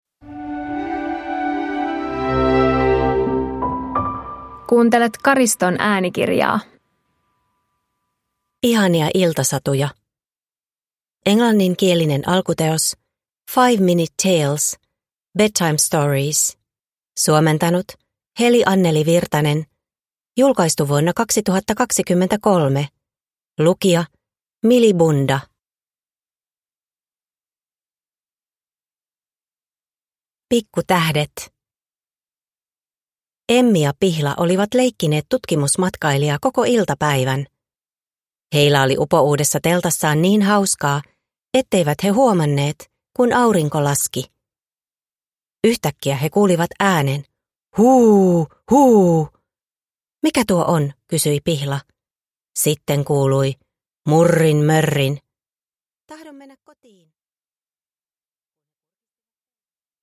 Ihania iltasatuja – Ljudbok